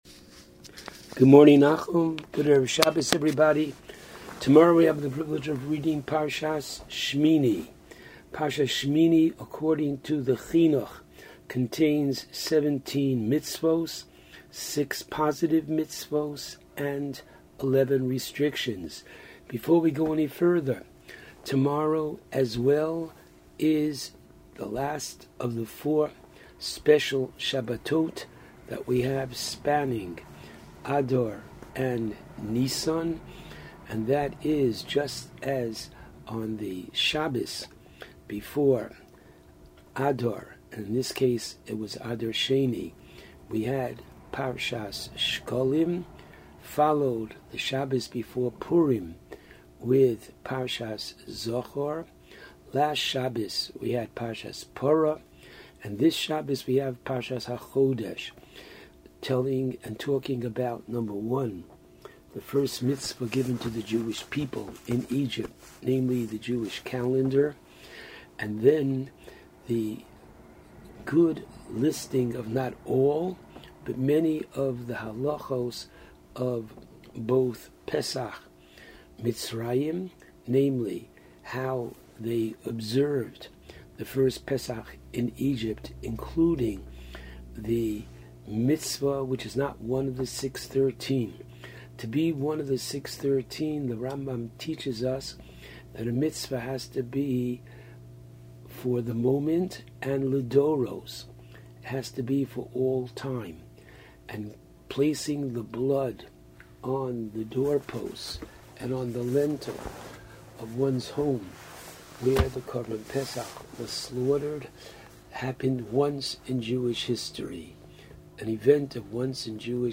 called in to JM in the AM to discuss parshas Shemini.